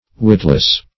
Witless \Wit"less\, a.